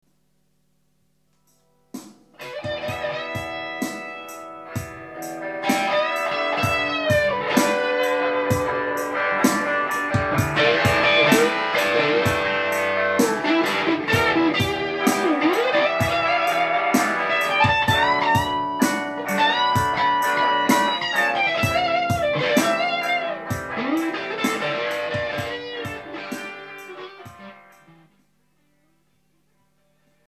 591KB（30秒）→拙いバッキングの上にいいソロが乗った場合